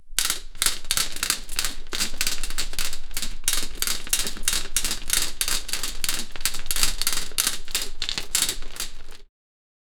Bed creaks as someone gets up; slow, dragging footsteps on the floor. 0:47 A bamboo ladder dragging 0:10 Áudio realista de empada macia por dentro e levemente crocante por fora sendo cortada com faca sobre tábua de madeira.
a-bamboo-ladder-dragging-53xakohm.wav